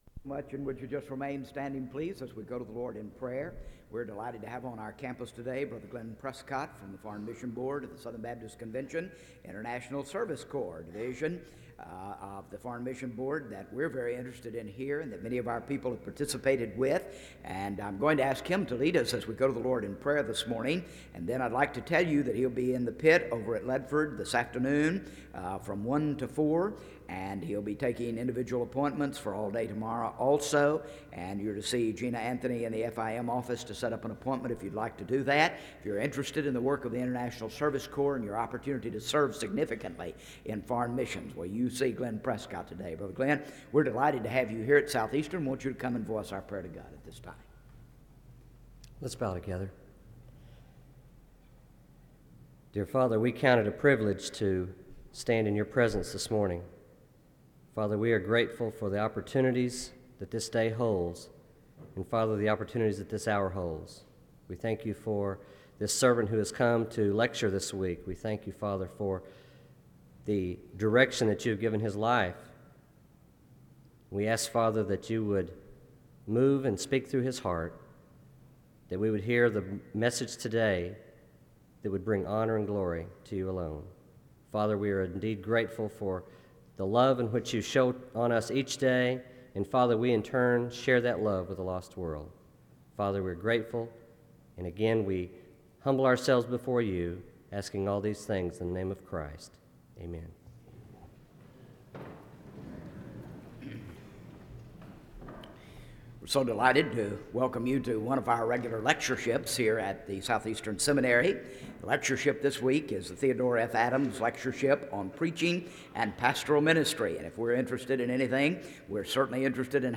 SEBTS Spring Lecture